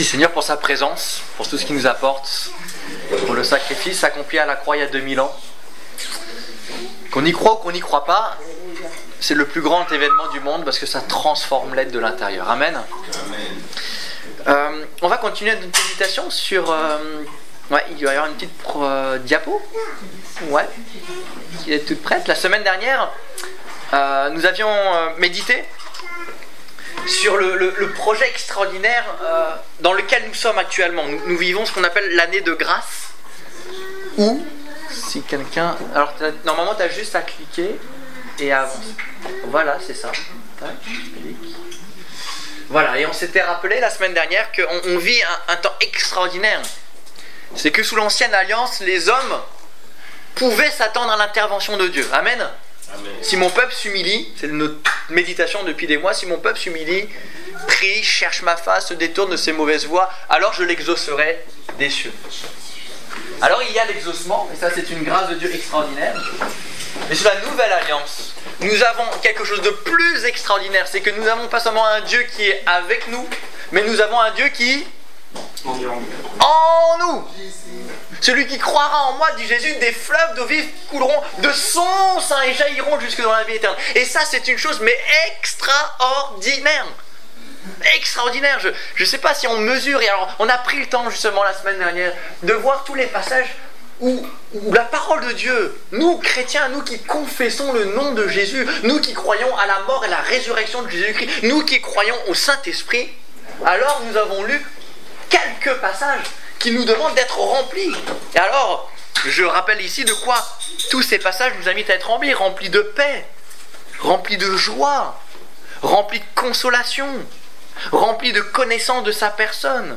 Être témoin Détails Prédications - liste complète Culte du 15 février 2015 Ecoutez l'enregistrement de ce message à l'aide du lecteur